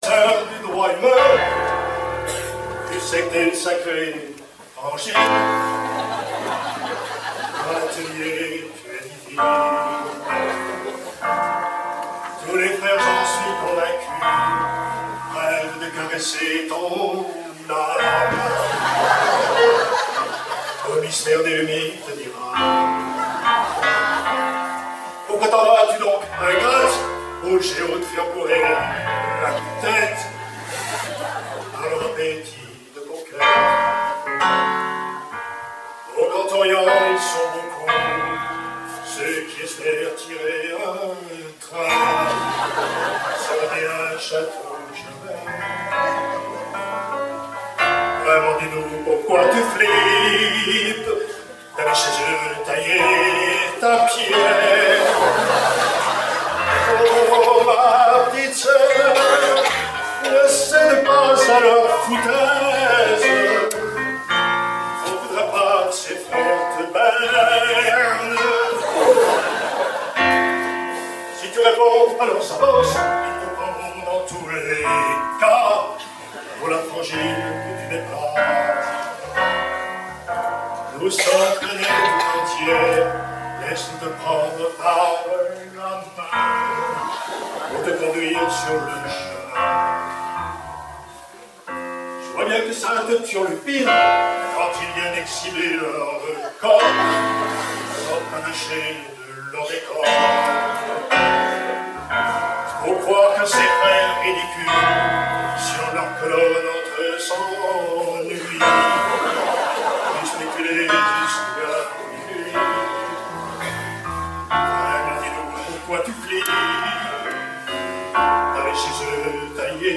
Enregistrement public, Festival 2023